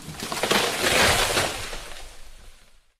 tree.ogg